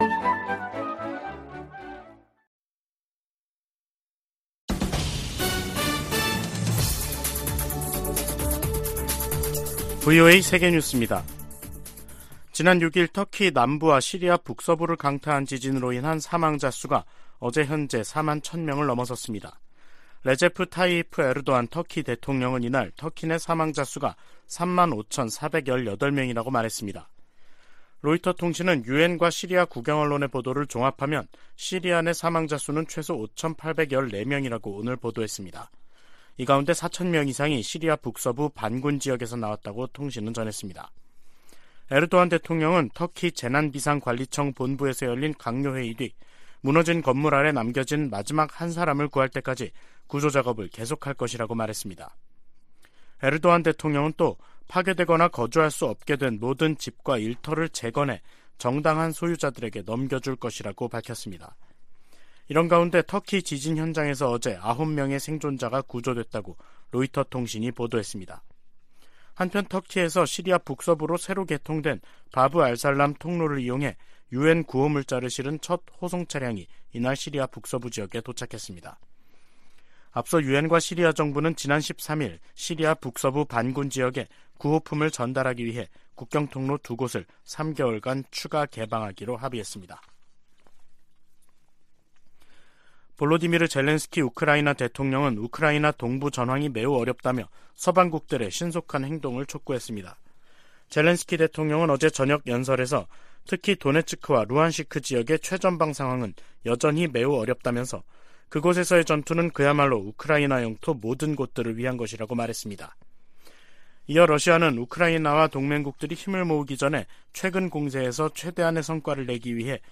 VOA 한국어 간판 뉴스 프로그램 '뉴스 투데이', 2023년 2월 15일 3부 방송입니다. 미 국무부가 중국의 정찰풍선 문제를 거론하며, 중국을 미한일 3국의 역내 구상을 위협하는 대상으로 규정했습니다. 백악관은 중국의 정찰풍선 프로그램이 정부의 의도와 지원 아래 운용됐다고 지적했습니다. 북한은 고체연료 ICBM 부대를 창설하는 등, 핵무력 중심 군 편제 개편 움직임을 보이고 있습니다.